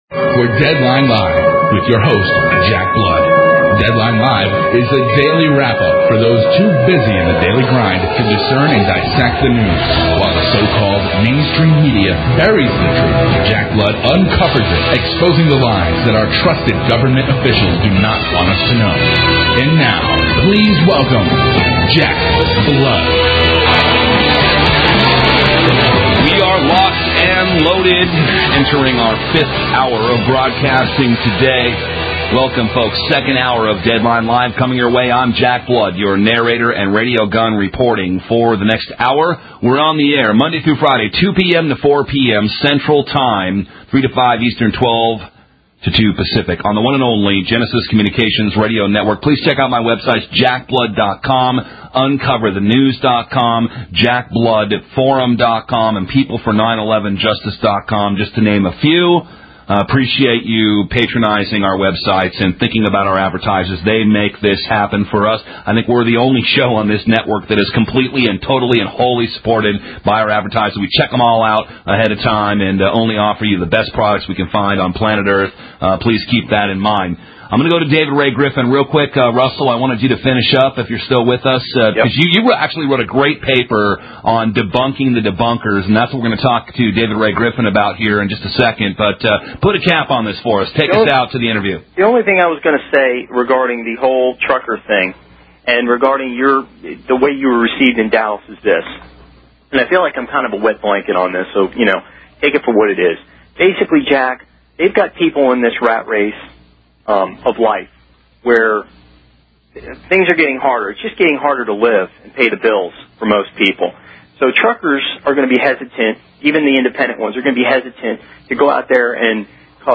9/11 Truth Movement leader speaks about his latest book.